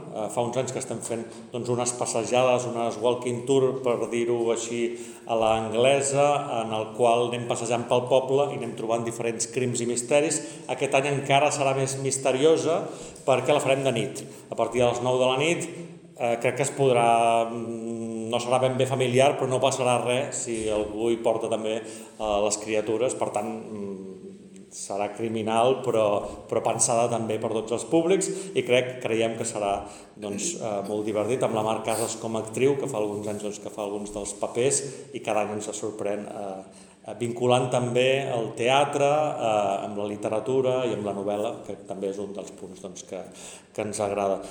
“Des de fa uns anys estem intentant enfortir aquesta part de presència al carrer”, ha explicat l’alcalde de Tiana, Isaac Salvatierra, durant la roda de premsa de presentació que s’ha fet aquest dimecres 14 de gener: